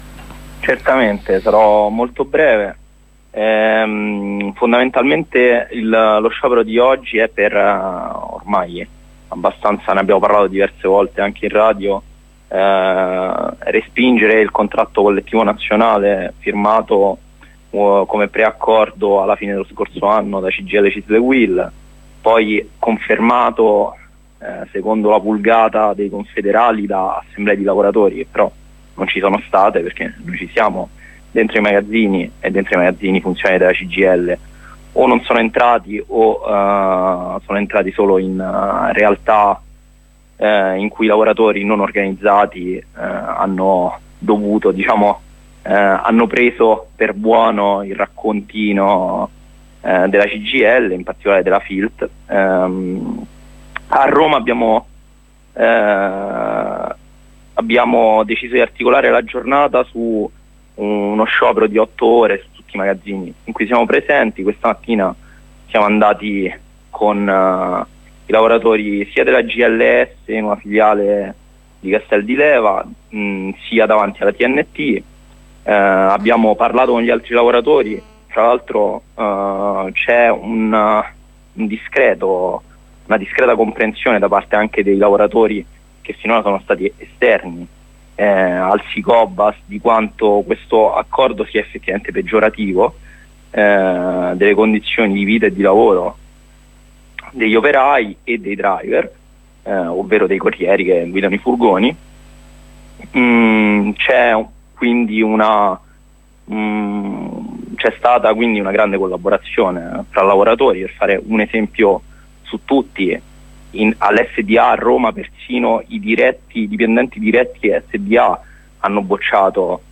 Corrispondenza con un insegnante della scuola primaria; Corrispondenza con un lavoratore Sda delegato Si Cobas; Corrispondenza con un compagno del coordinamento romano del Si Cobas.